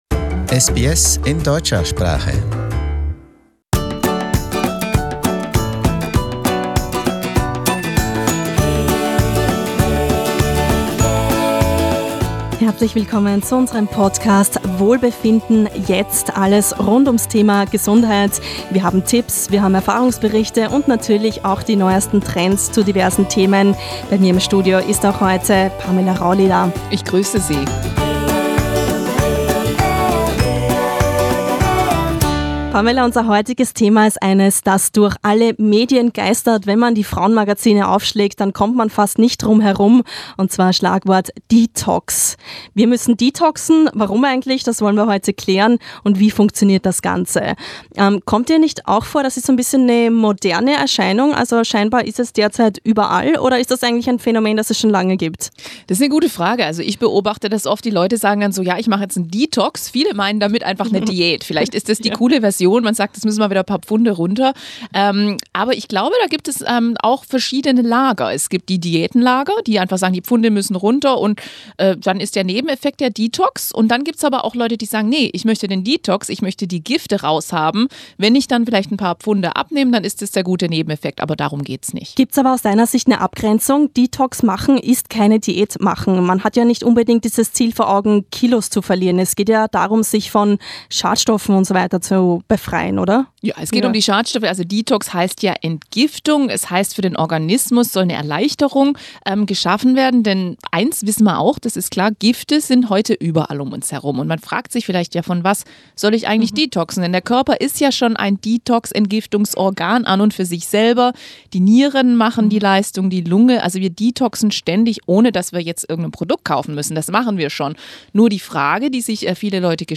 Unserem Organismus die Möglichkeit zu geben, von Schadstoffen zu entgiften, hat viele Vorteile, muss aber gleichzeitig mit Sorgfalt durchgeführt werden. In dieser Episode hören wir von einer Deutschen in Australien, die kürzlich einen Detox ausprobiert hat.